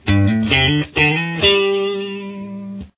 Below are some examples of licks to play that cross over from pattern to pattern using primarily the pentatonic minor lead pattern.